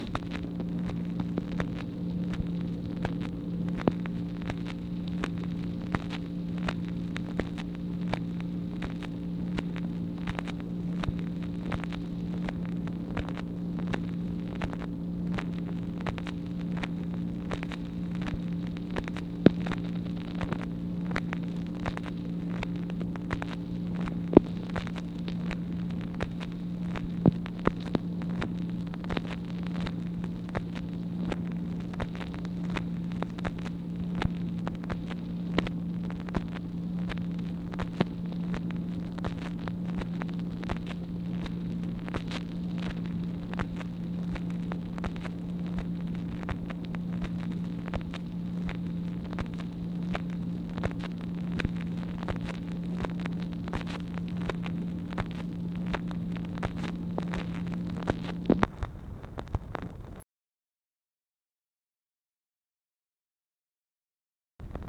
MACHINE NOISE, April 29, 1964
Secret White House Tapes | Lyndon B. Johnson Presidency